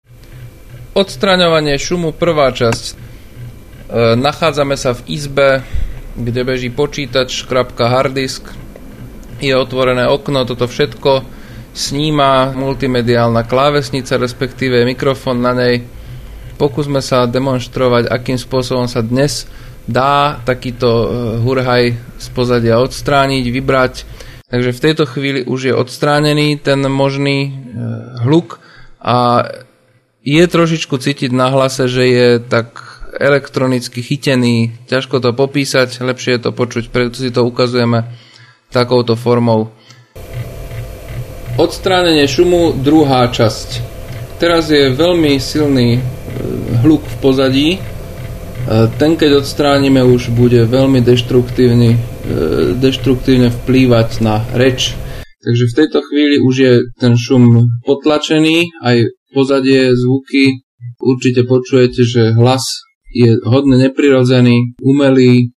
Nahral som aj na ukß╛ku pßr slov, ktorΘ som nßsledne od╣umil, aby ste si utvorili obraz o mo╛nostiach bez toho, aby ste sa s t²m museli babra╗.
Na ukß╛ke je prvß Φas╗ nahranß tak, ╛e som na mikrof≤n hovoril zblφzka, zvuk pozadia je pomerne slab², Jeho od╣umenie je skoro bezvadnΘ a straty kvality hlasu s· malΘ. Nßslednß druhß Φas╗ je nahranß u╛ z vΣΦ╣ej vzdialenosti, odstup ╣umu a ruchov je nφzky (Φi╛e hlasn² hluk v pozadφ). Jeho odstrßnenie je sφce takmer bezvadnΘ, ale na hlase u╛ zrete╡ne cφti╗, ╛e je neprirodzen².
noisredukcia.mp3